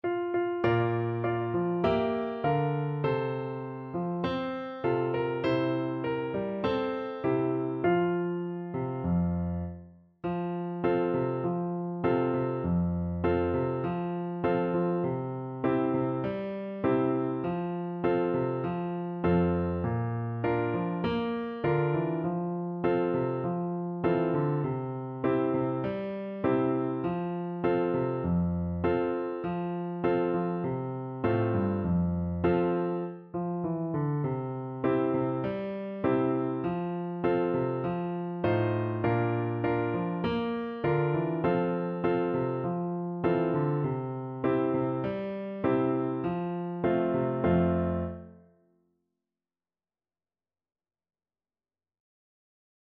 Flute
F major (Sounding Pitch) (View more F major Music for Flute )
Moderato
F5-F6
Traditional (View more Traditional Flute Music)
Indonesian